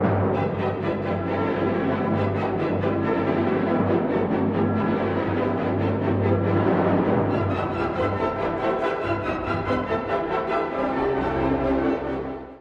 (序奏) 古い音源なので聴きづらいかもしれません！
主部はAllegro vivace。
チャイコらしい豪華なシーンの連続
嵐のような合奏、優美な木管の主題が続きます。